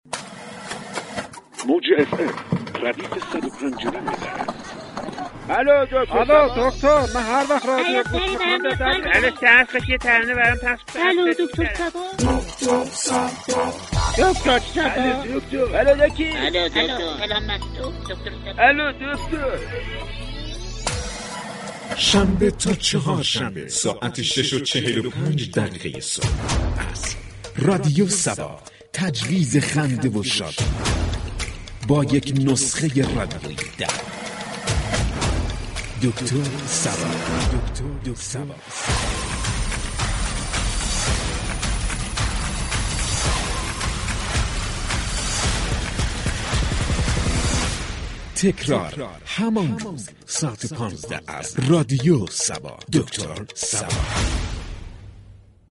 رادیو صبا در برنامه «دكتر صبا» با نگاهی طنز به آینده می پردازد